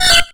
Cri de Fouinette dans Pokémon X et Y.